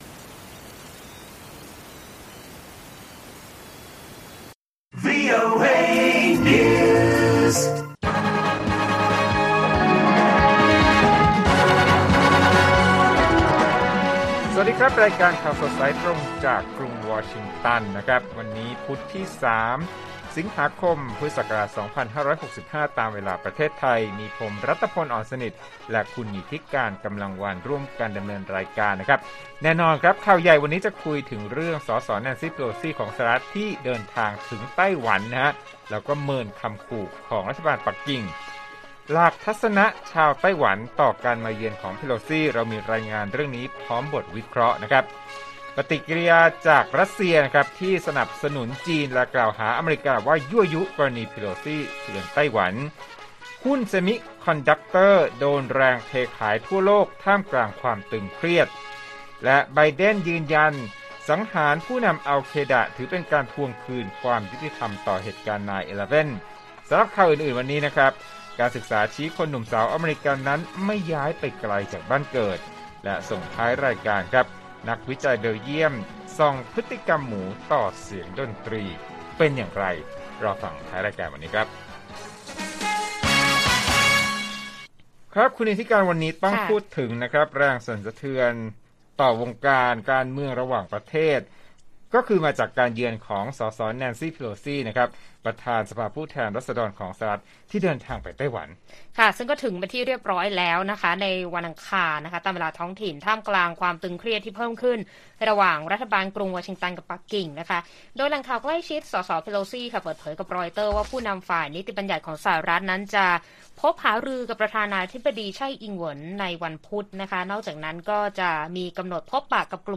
ข่าวสดสายตรงจากวีโอเอไทย 6:30 – 7:00 น. วันที่ 3 ส.ค. 65